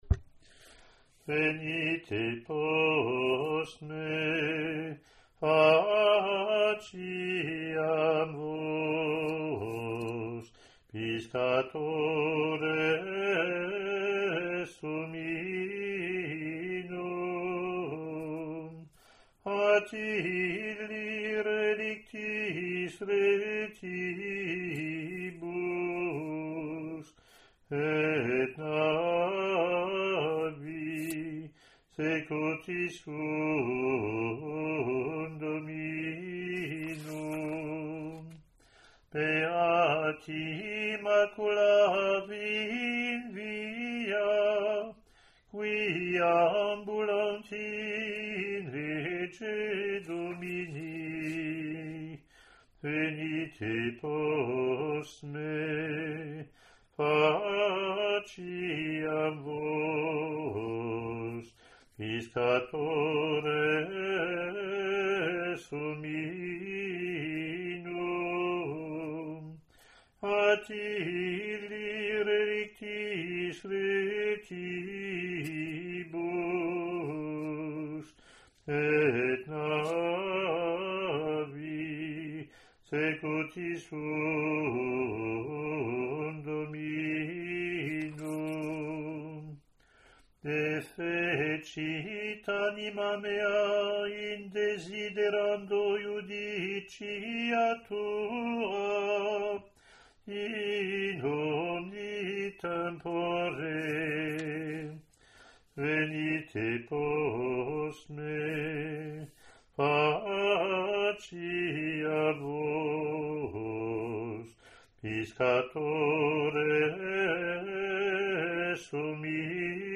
1974 Roman Gradual – Ordinary Form of the Roman Rite
Latin antiphon + verses )